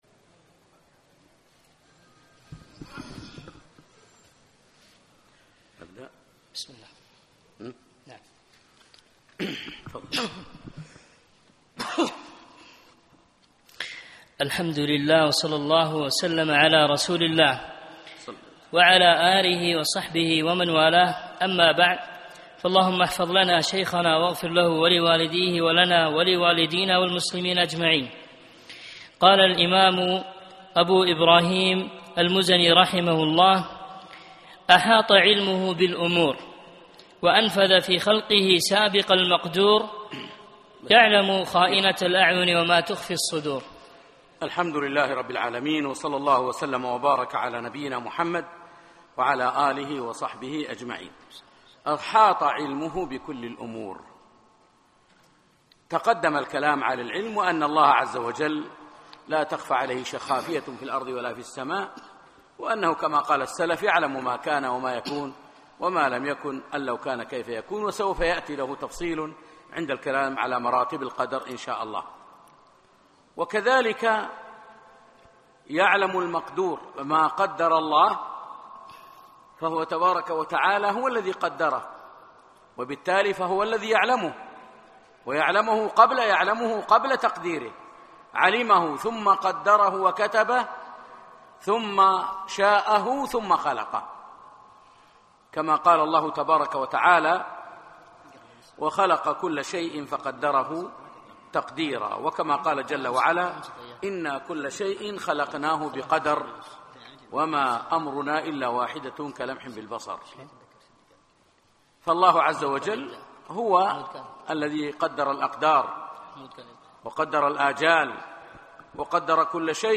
السبت 1 4 2017 بعد صلاة العشاء مسجد صالح الكندري صباح السالم